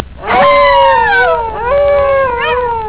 Wolves
howl.wav